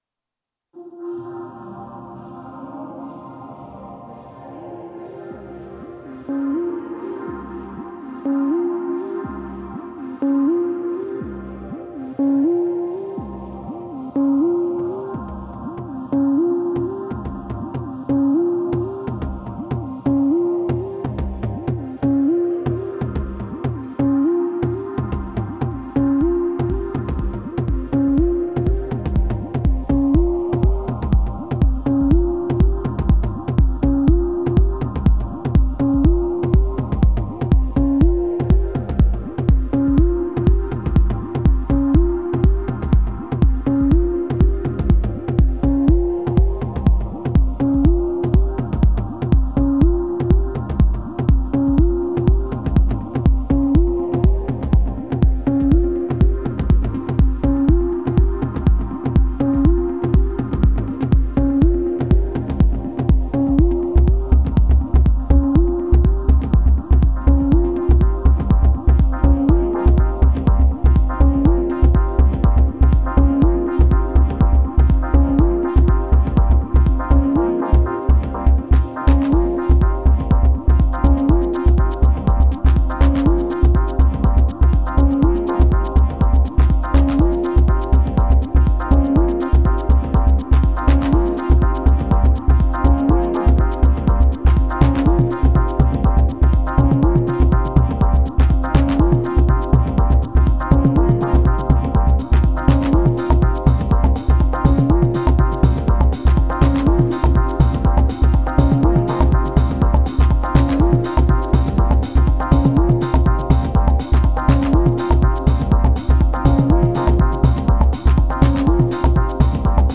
This was done in one pass, printed as a single stereo recording of the live mix off the Mackie mixer.
I feel like your setup is a great mix of solid foundation sounds and boxes that provide an added layer of ‘experimenting fun’ type of boxes.
I don’t really think of it as a track, more just a loop jam - glad you enjoyed it!